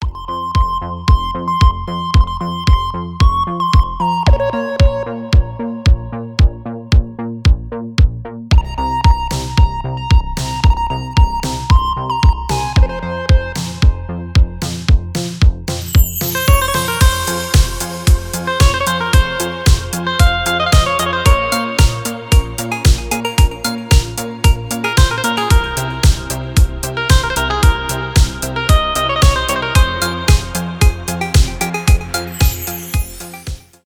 без слов
танцевальные